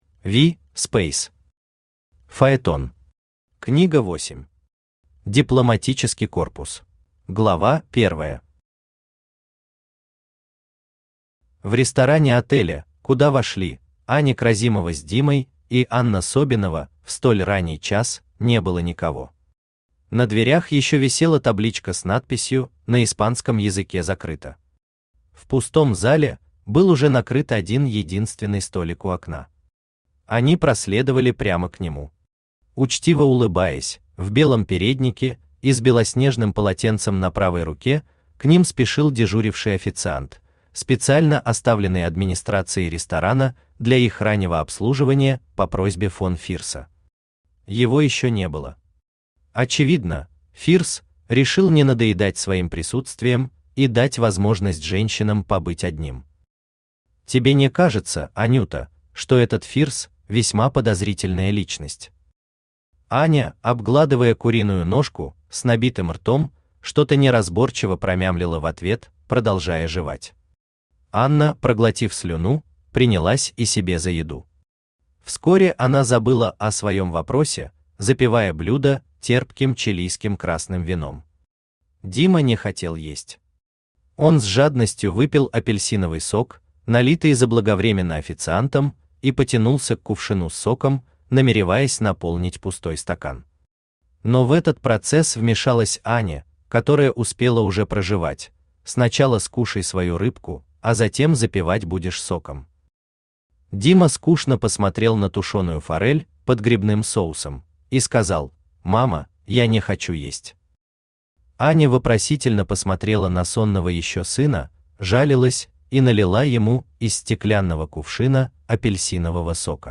Аудиокнига Фаетон. Книга 8. Дипломатический корпус | Библиотека аудиокниг
Дипломатический корпус Автор V. Speys Читает аудиокнигу Авточтец ЛитРес.